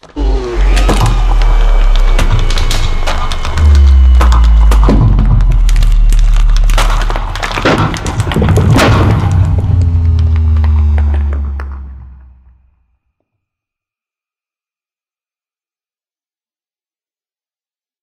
growl.ogg